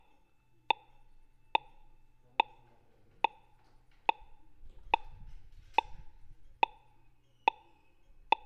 Auffindesignal
Das langsame Klopfen oder Tackern (tack – tack – tack) hilft, den Ampelmast zu finden, es nennt sich daher „Auffindesignal". Dieses Signal ist bei eingeschalteter Ampel dauerhaft in Betrieb.
Die akustischen Signale wurden uns freundlicherweise von der Firma RTB zur Verfügung gestellt.